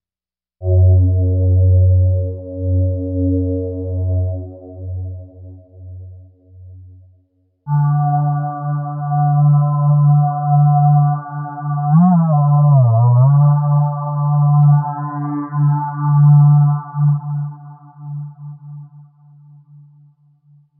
描述：用REAKTOR制作的古怪循环和垫子
Tag: 92 bpm Weird Loops Fx Loops 2.59 MB wav Key : Unknown